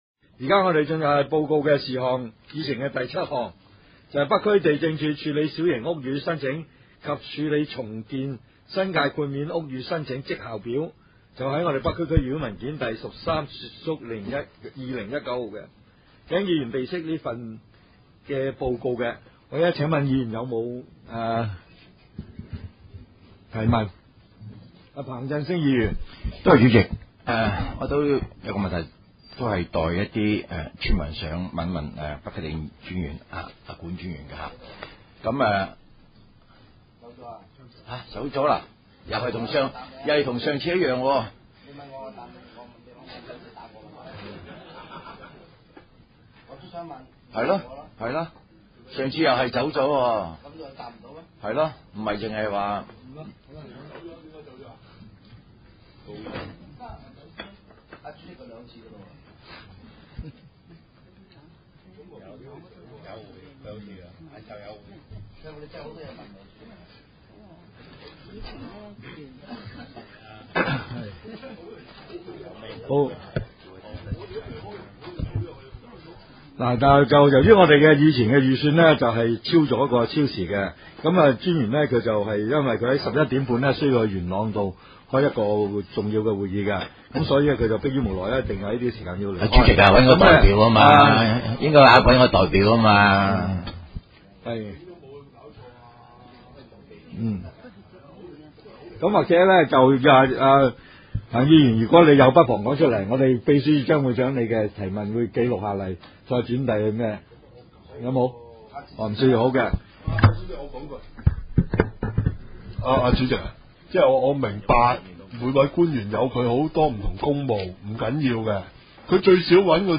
区议会大会的录音记录
北区区议会会议室